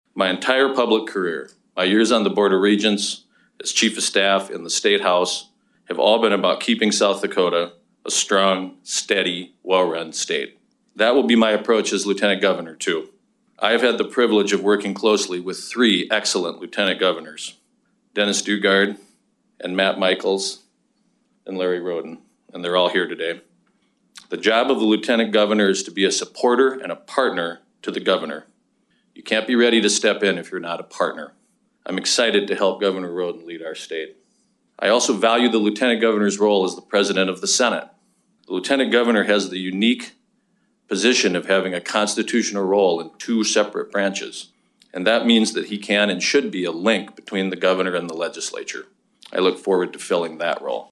Venhuizen says he’s always felt called to public service.